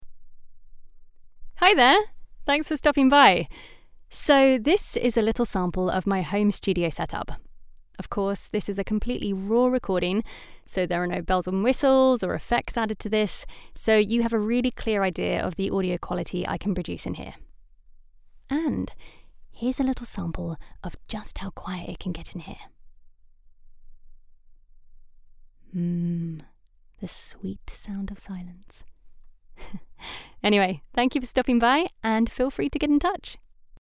Female
Bright, Character, Cool, Engaging, Friendly, Natural, Soft, Warm, Witty, Versatile, Approachable, Conversational, Funny, Upbeat, Young
Microphone: Rode NT1-A
Audio equipment: Focusrite Scarlett 2i2, bespoke built vocal isolation booth